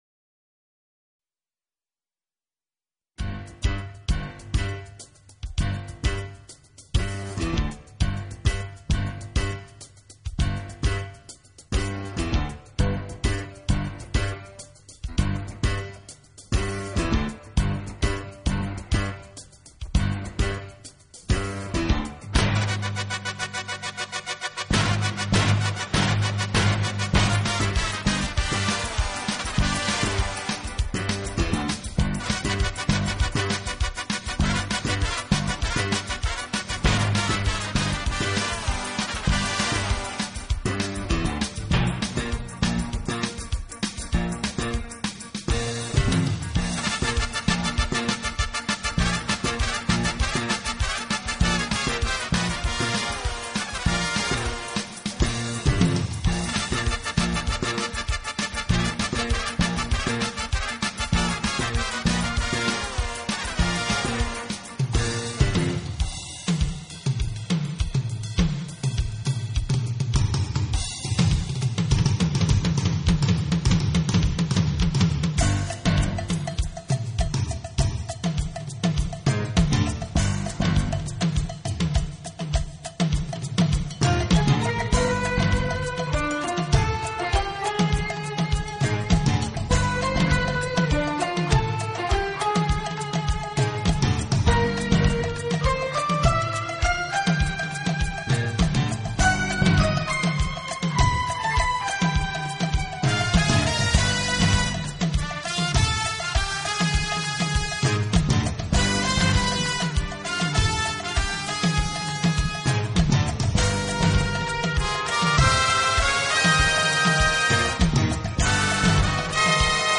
管乐组合，给人以美不胜收之感。